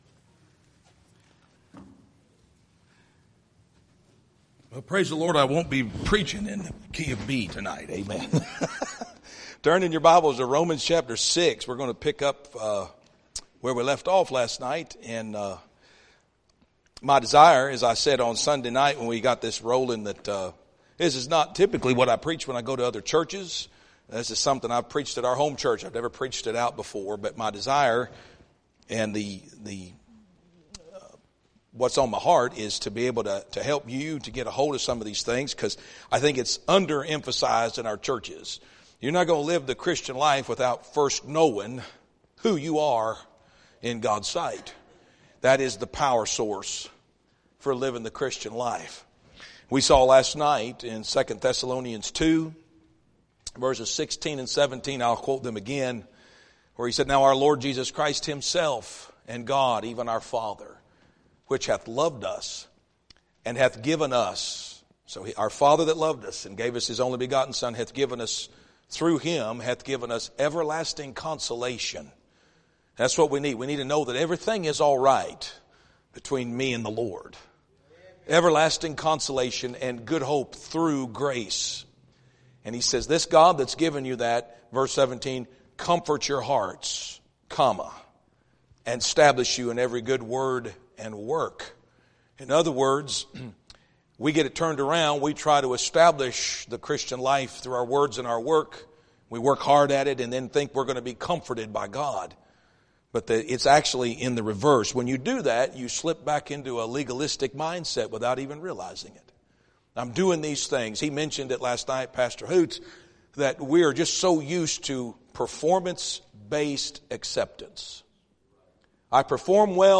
2026 Revival Meeting